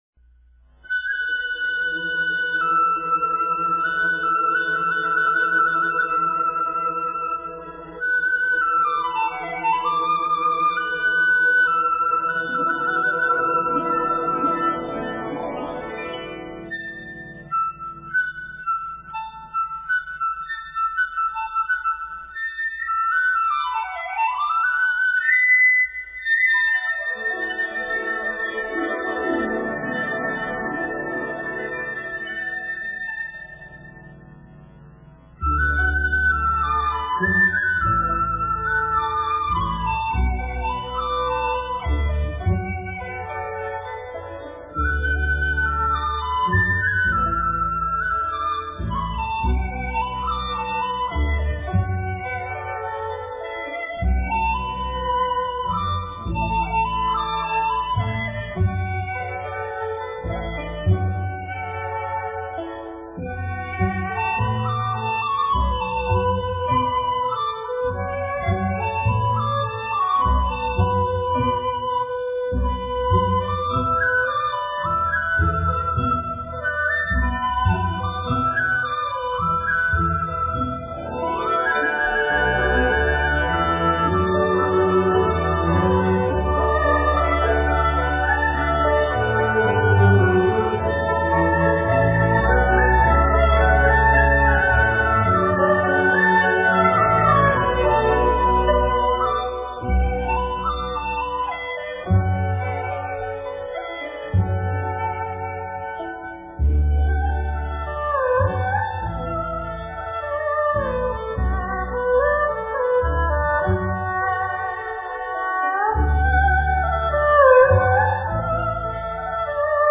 屬角調式 以大陸西南地區民歌及蘇州彈詞音調為題材 生氣蓬勃的樂風